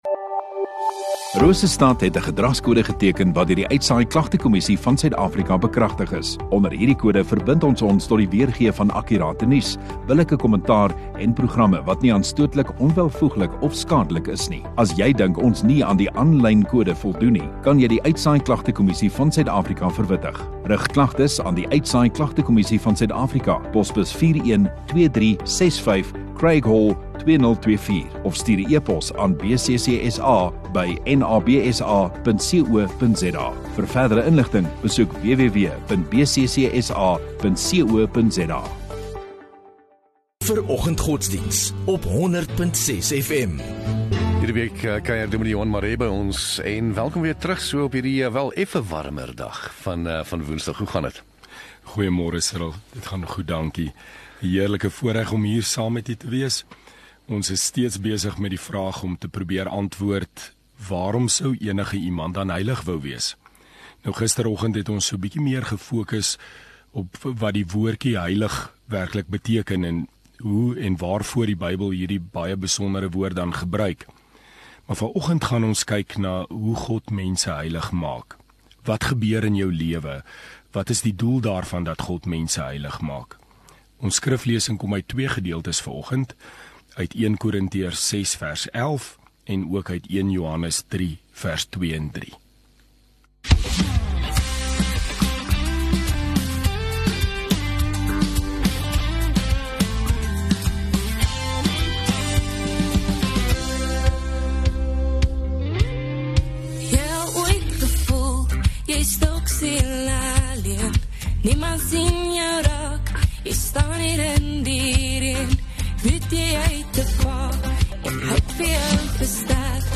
10 Jul Woensdag Oggenddiens